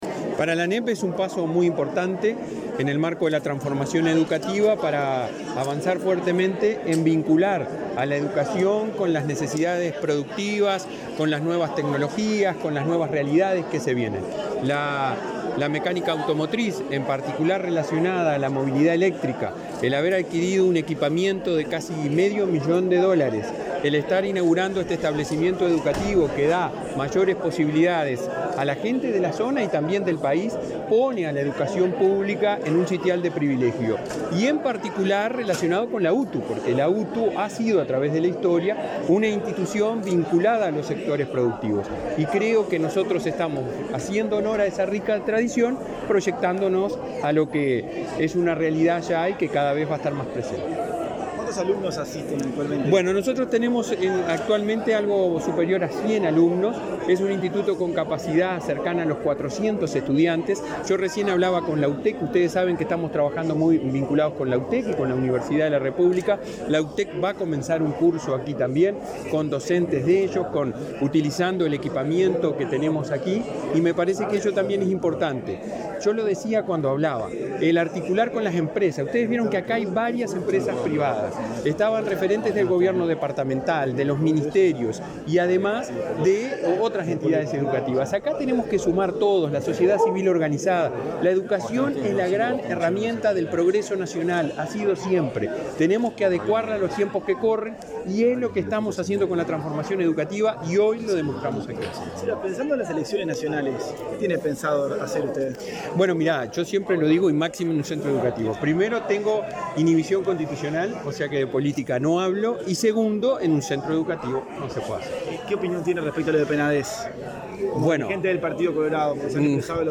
Declaraciones del presidente de la ANEP, Robert Silva
Declaraciones del presidente de la ANEP, Robert Silva 12/10/2023 Compartir Facebook X Copiar enlace WhatsApp LinkedIn El director general de UTU, Juan Pereyra, y el presidente de la ANEP, Robert Silva, participaron de la inauguración del Instituto de Alta Especialización (IAE) en electromovilidad, en la localidad de Las Piedras. Luego, Silva dialogó con la prensa.